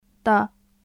/d/
دولاب /dolaːb/ タンス